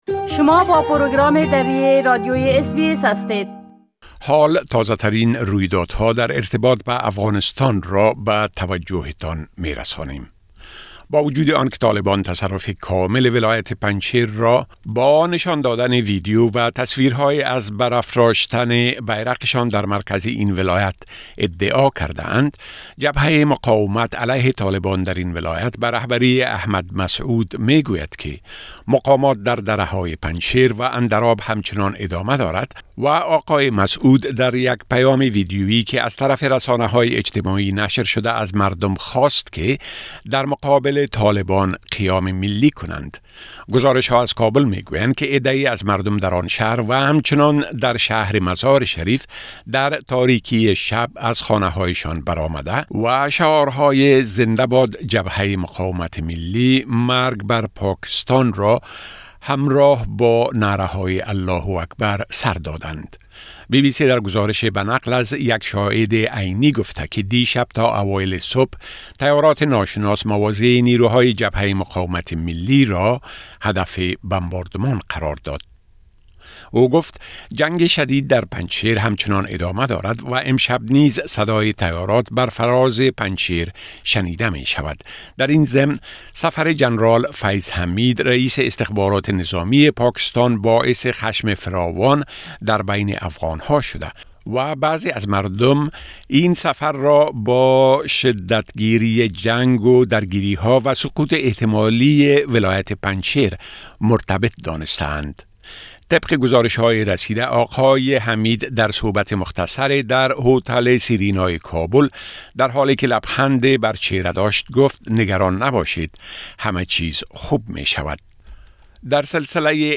گزارش كامل از تازە ترين رويدادھاى مهم در ارتباط بە افغانستان، را در اينجا شنيده ميتوانيد.